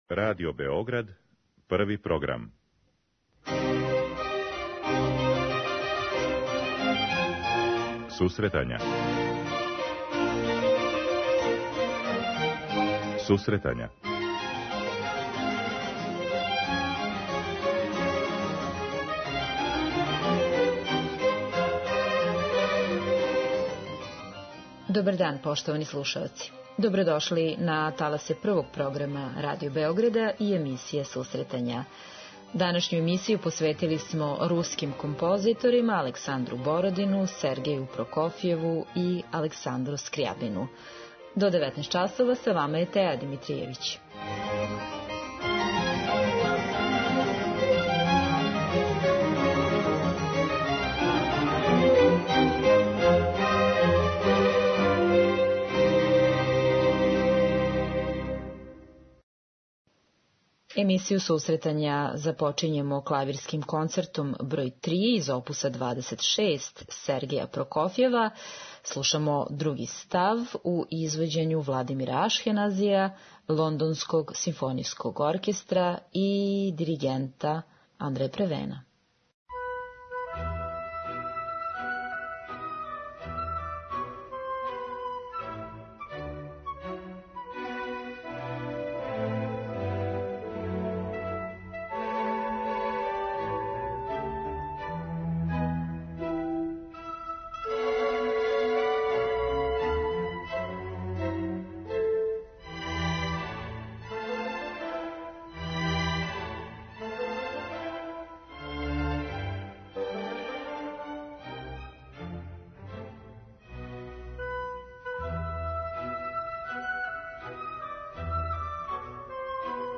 Емисију посвећујемо симфонијској и солистичкој музици
Музичка редакција Емисија за оне који воле уметничку музику.